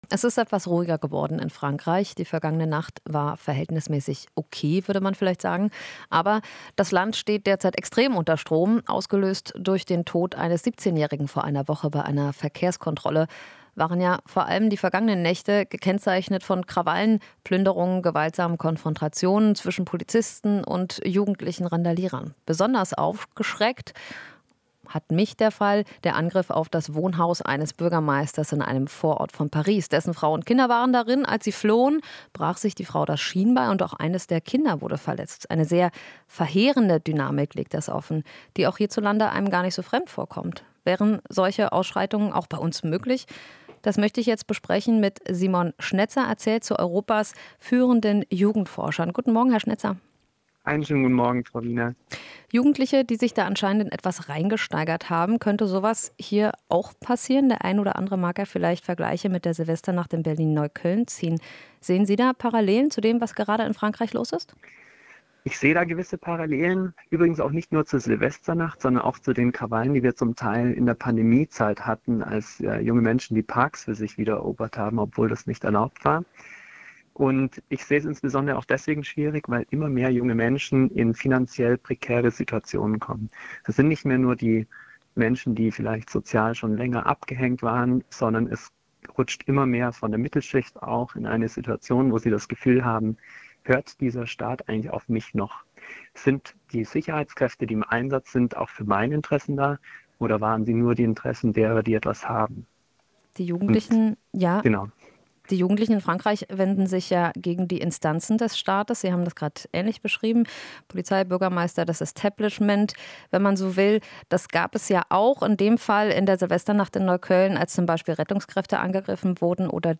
Interview - Jugendforscher: Randalierer haben das Gefühl, nichts verlieren zu können